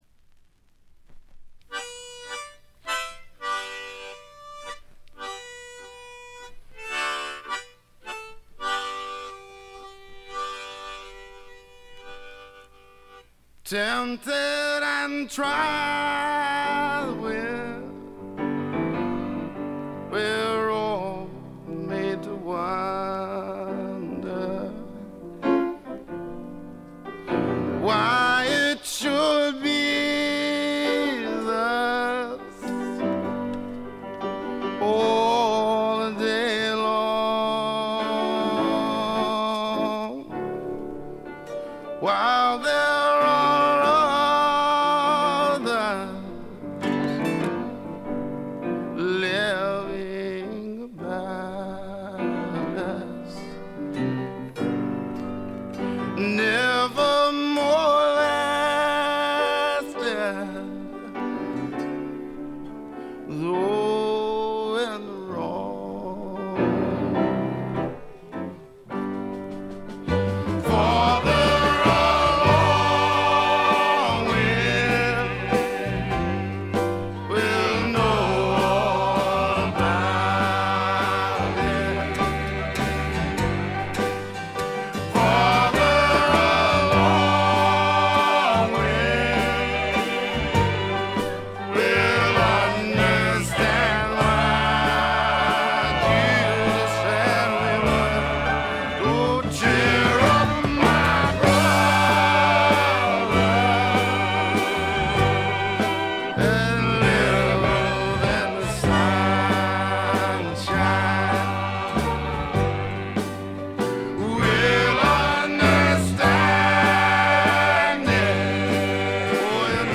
微細なバックグラウンドノイズ程度。
知る人ぞ知るゴスペル・スワンプの名作！
リードシンガーは男２、女２。
試聴曲は現品からの取り込み音源です。